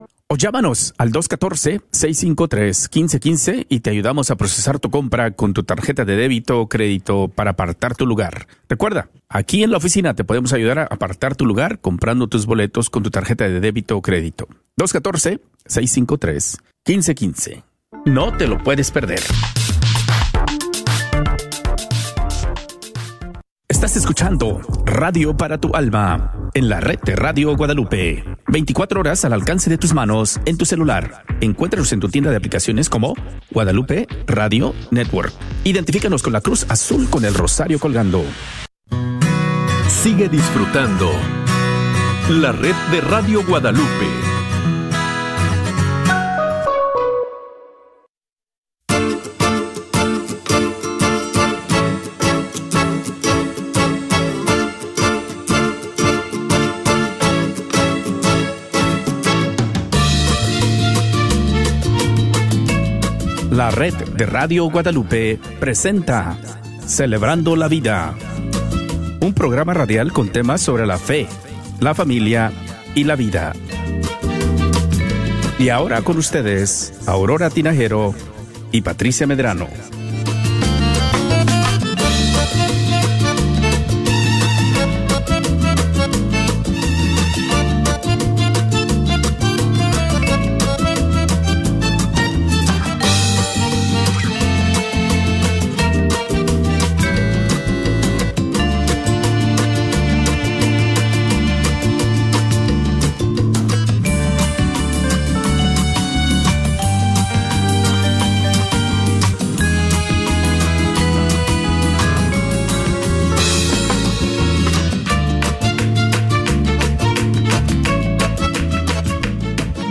Este programa fue emitido por primera vez el Martes 20 de mayo